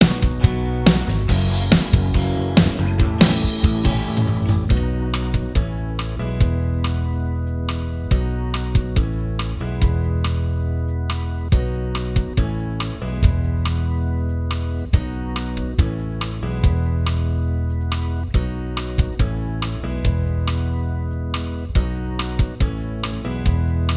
Minus All Guitars Soft Rock 3:45 Buy £1.50